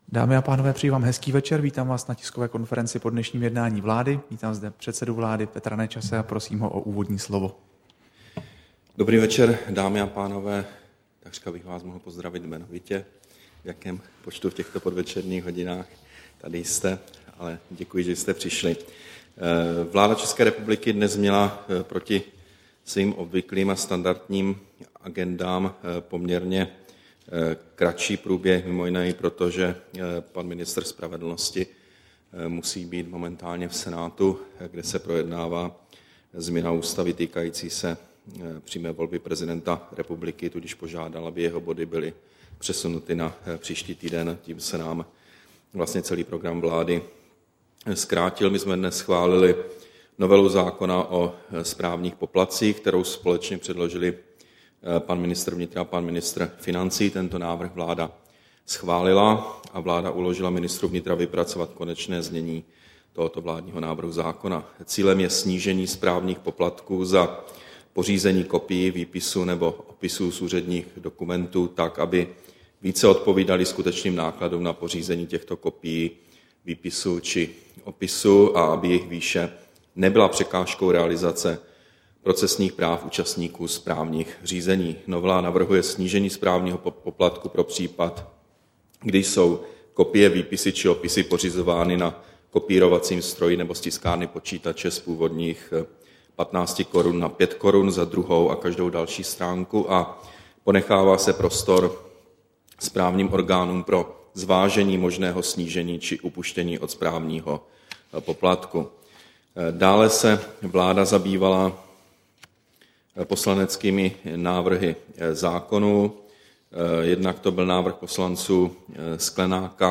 Tisková konference po jednání vlády, 8. února 2012